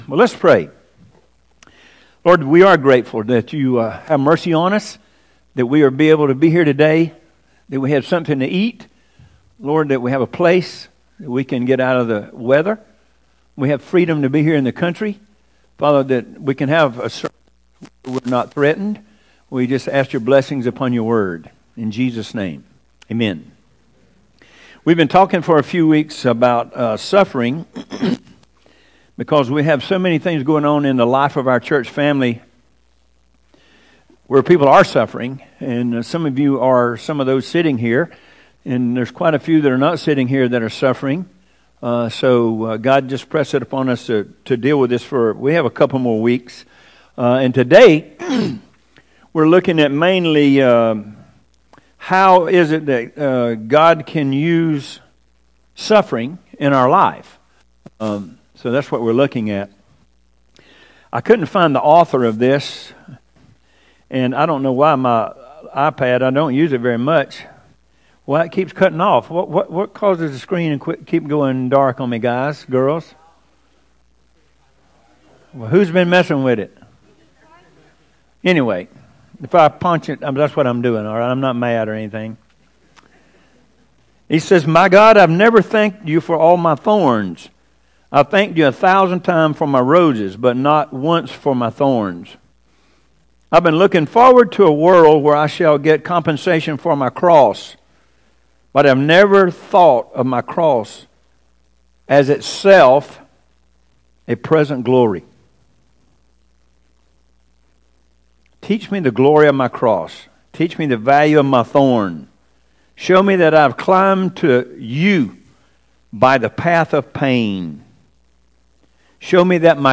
Weekly sermons from Harmony Community Church in Byron, Georgia.
0302Sermon.mp3